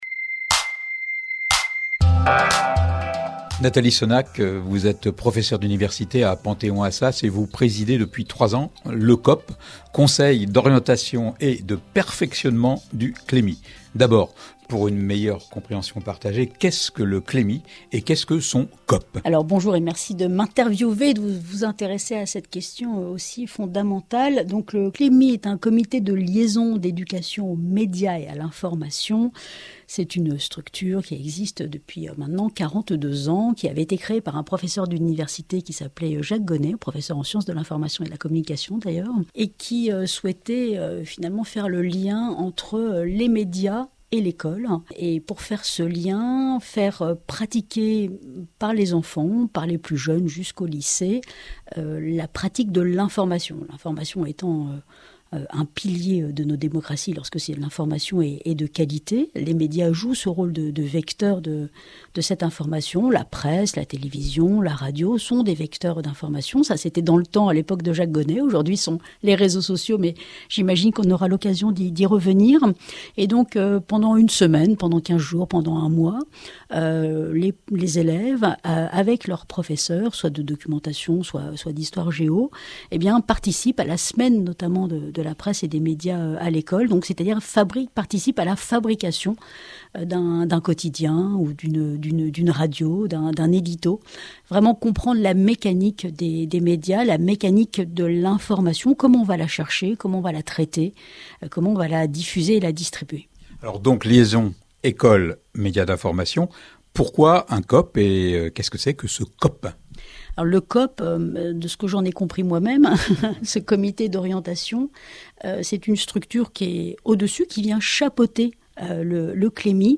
Un Conseil National de l'EMI? Entretien avec Nathalie SONNAC